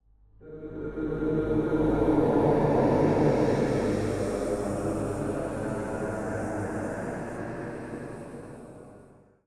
ساخت صدای محیط (ترسناک)